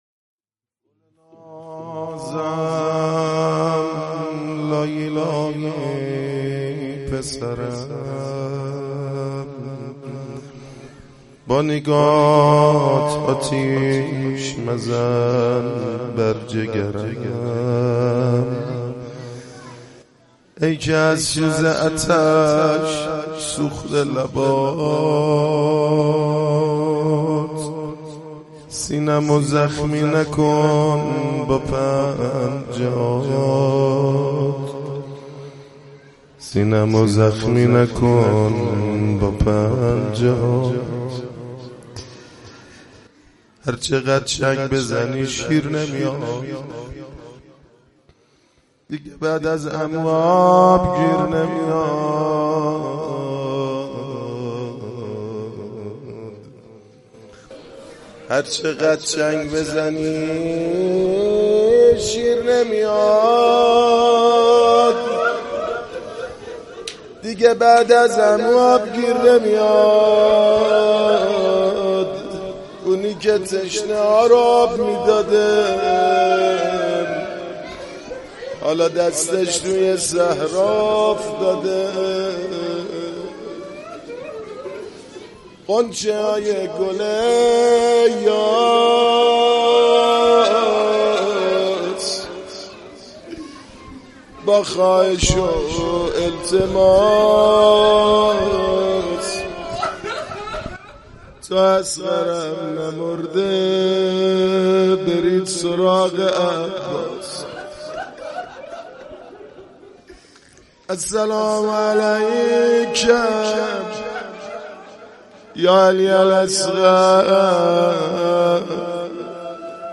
مداحی شب هفتم محرم 98 ( قسمت )